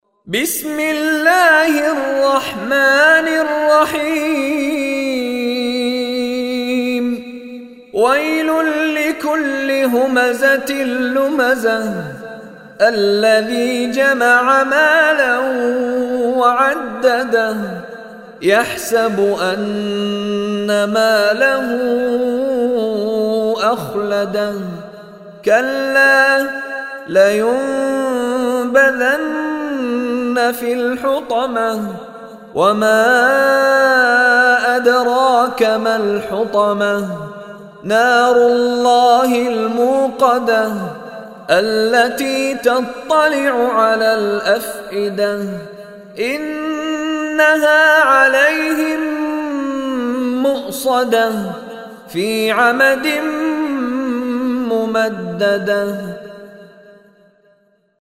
Surah Humazah Recitation by Mishary Rashid
Listen online and download beautiful tilawat / Recitation of Surah Humazah in the beautiful voice of Sheikh Mishary Rashid Alafasy.